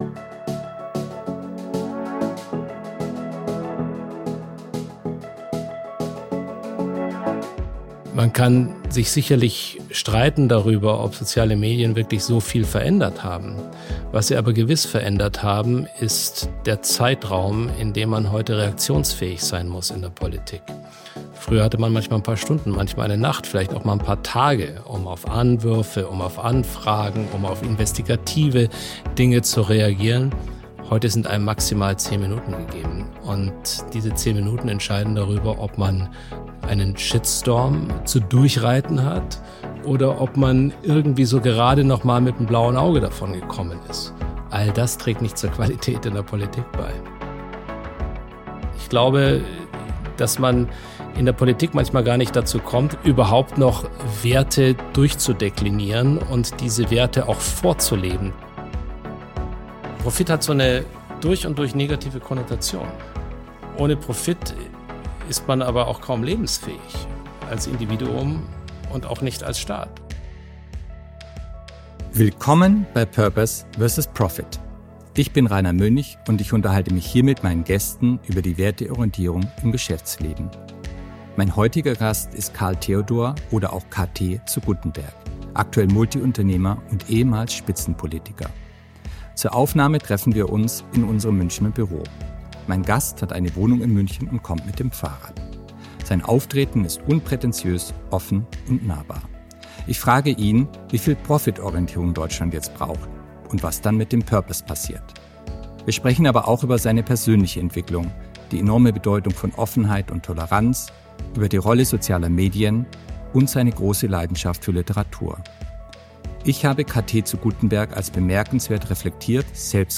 Beschreibung vor 8 Monaten Karl-Theodor zu Guttenberg ist zu Gast bei Purpose vs. Profit.